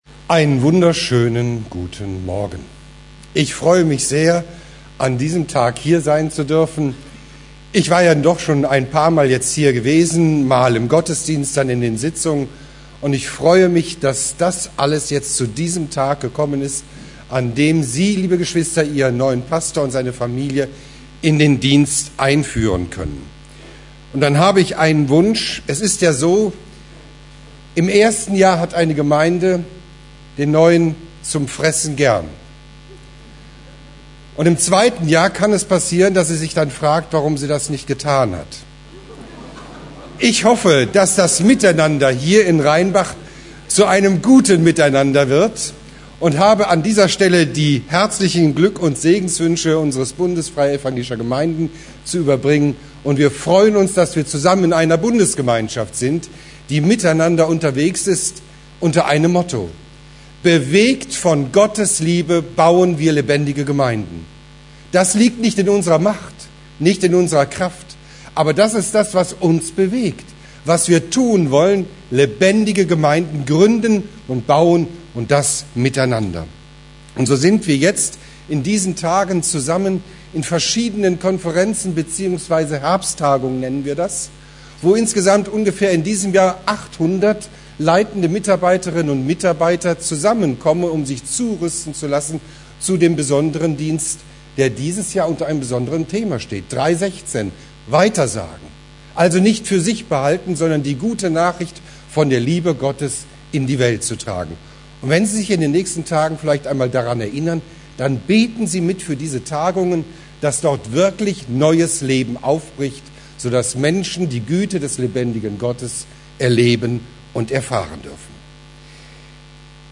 Serie: Einzelpredigten